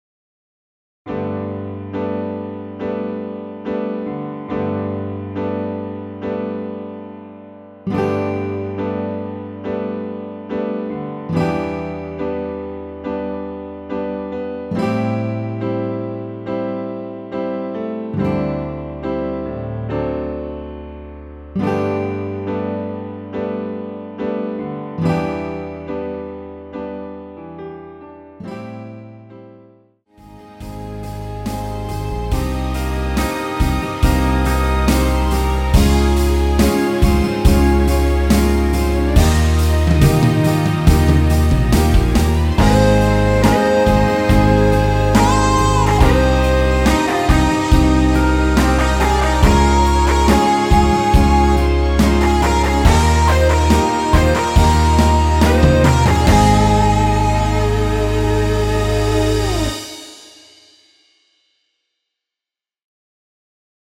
엔딩이 너무길고페이드 아웃이라 라이브 하시기 좋게 4마디로 편곡 하였습니다.(미리듣기 참조)
앞부분30초, 뒷부분30초씩 편집해서 올려 드리고 있습니다.